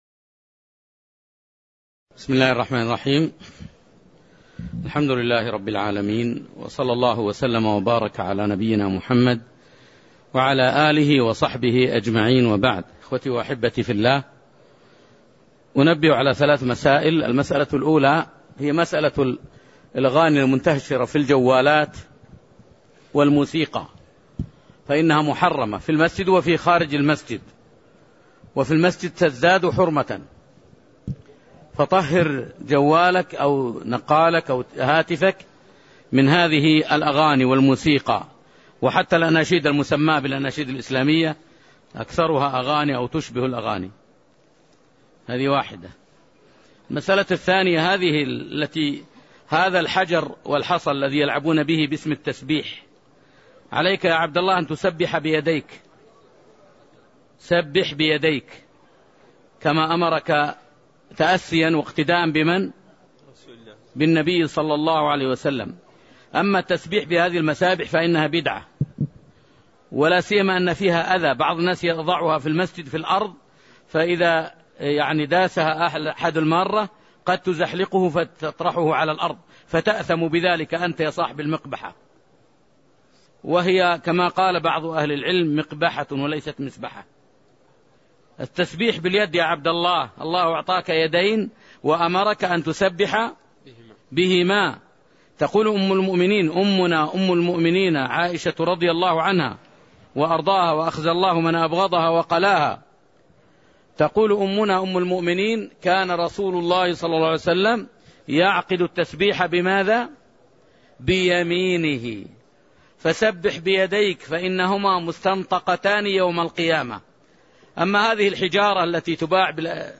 تاريخ النشر ٣ ذو الحجة ١٤٣٠ هـ المكان: المسجد النبوي الشيخ